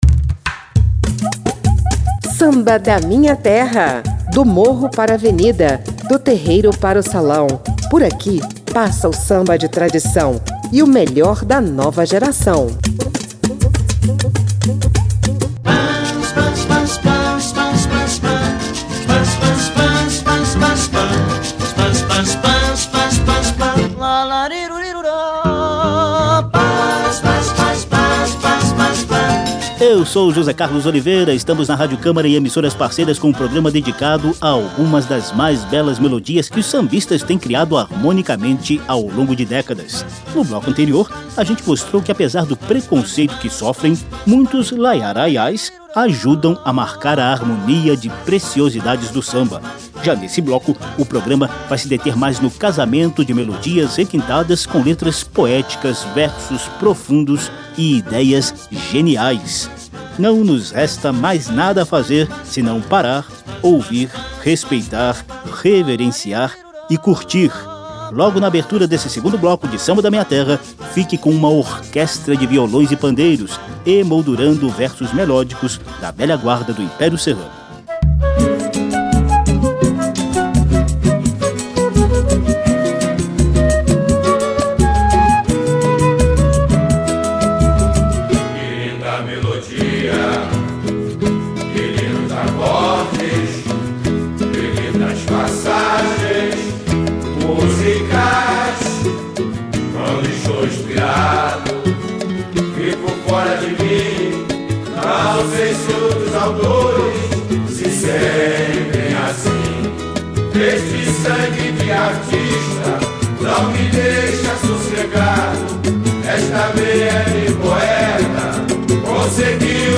Portanto, deixe o preconceito de lado e confira que os “laiá-raiás” do samba também são responsáveis por melodias e harmonias requintadas.